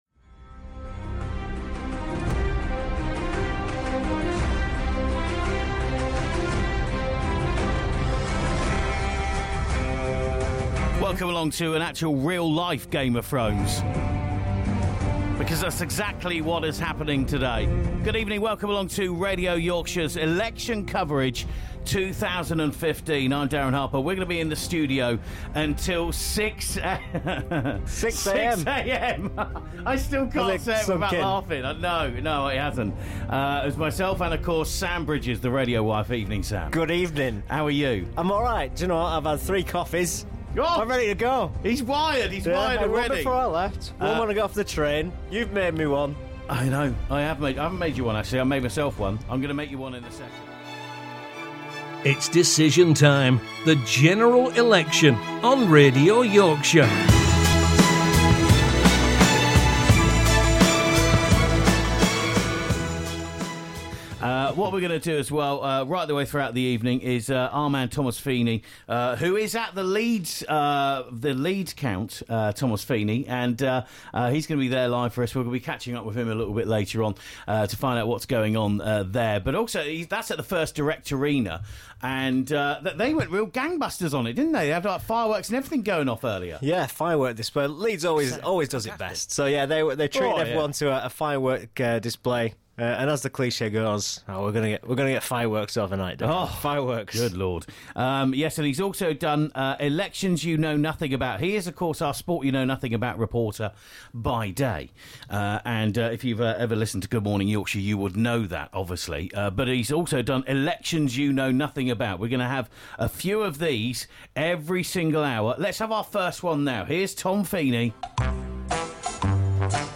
Missed out on our General Election night coverage ?